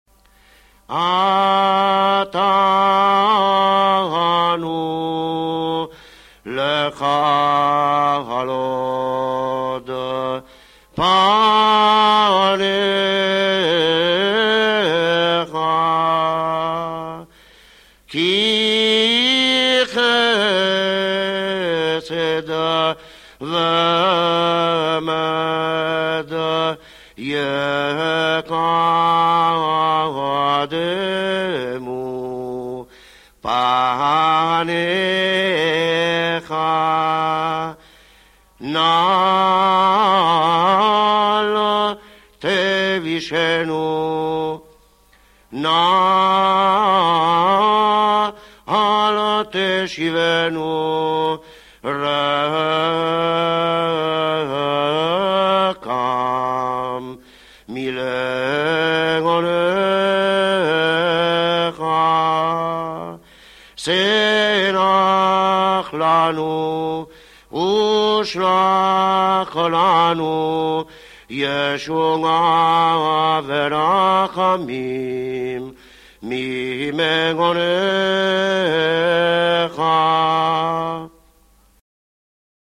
The following playlist features a selection of selichot performed in various Italian rites.